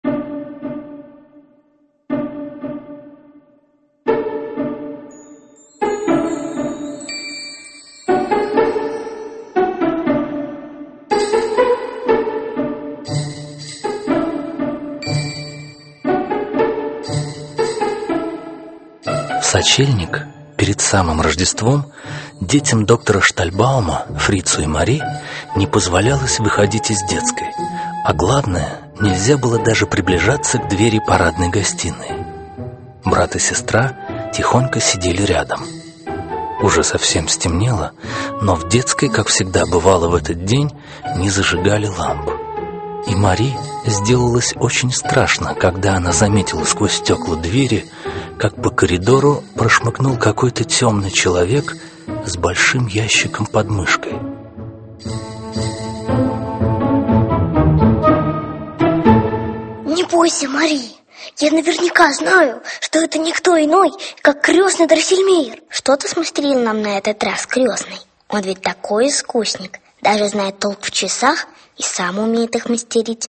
Аудиокнига Щелкунчик (спектакль) | Библиотека аудиокниг